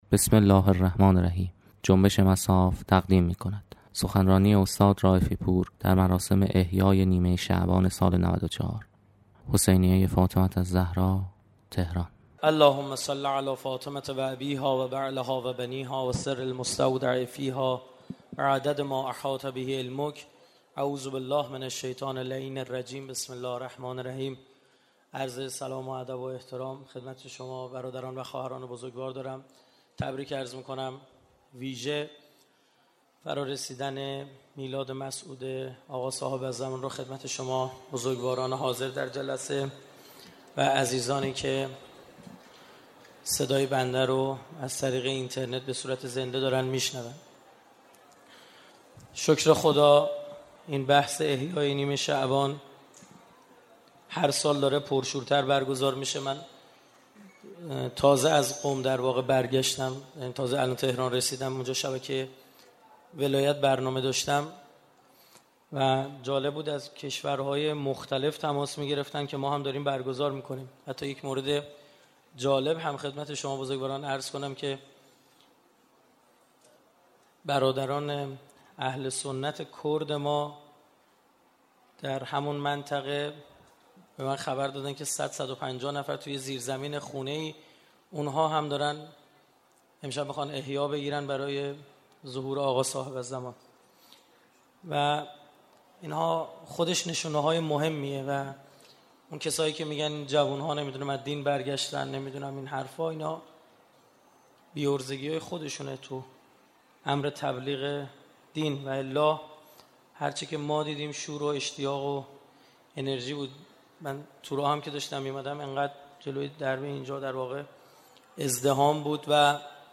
دانلود سخنرانی استاد رائفی‌پور نیمه شعبان ۹۴ – مجله نودیها